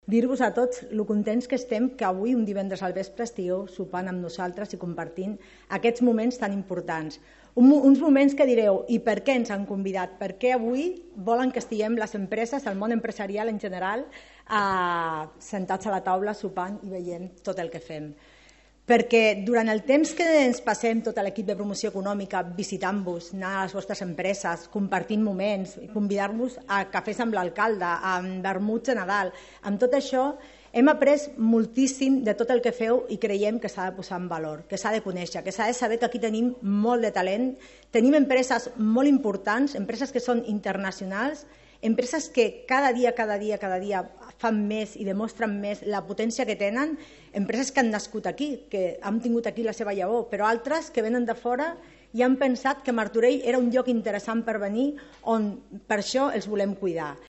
El Centre de Promoció Econòmica Molí Fariner ha acollit aquest divendres la 1a edició de ‘Martorell Reconeix: Empresa’, un acte impulsat per l’Ajuntament de Martorell per reconèixer públicament la trajectòria, l’esforç i la contribució del teixit empresarial del municipi.
Rosa Cadenas, regidora de Promoció Econòmica
Martorell-Reconeix-02.-Rosa-Cadenas.mp3